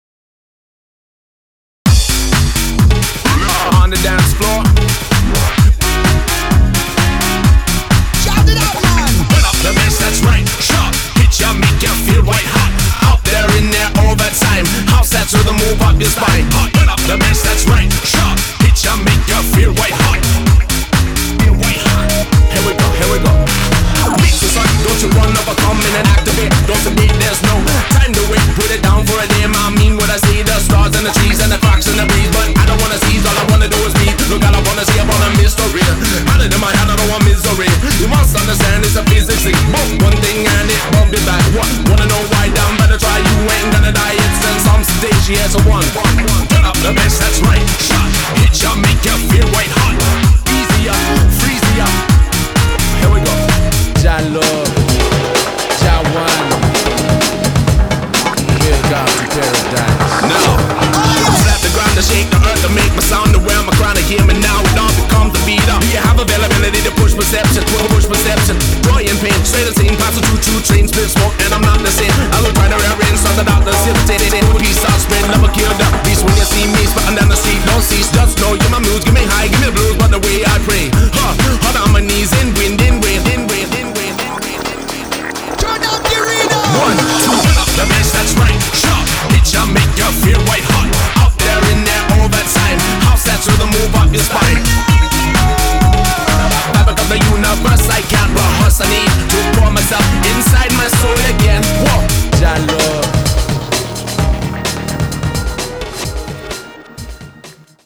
BPM129
Audio QualityPerfect (High Quality)